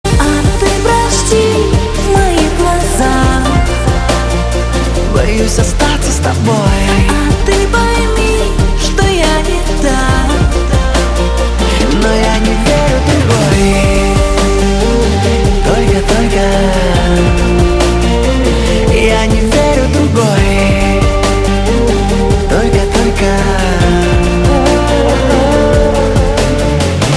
Поп музыка, Eurodance